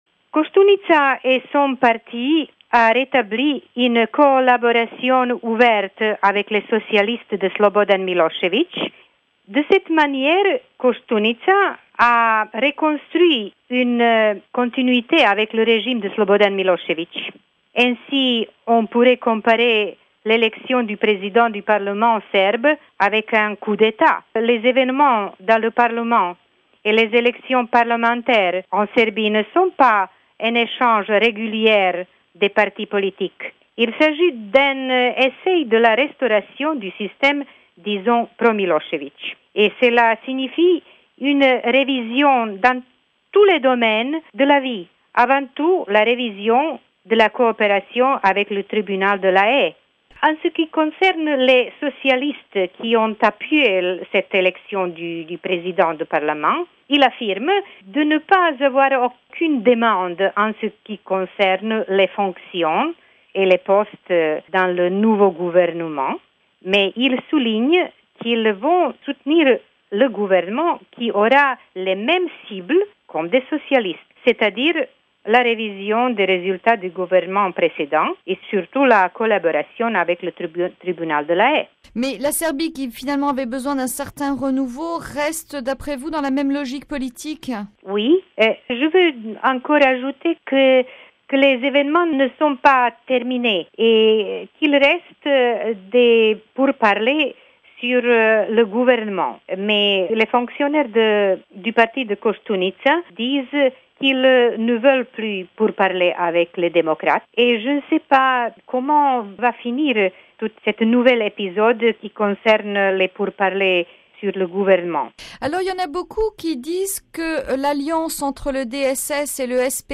journaliste serbe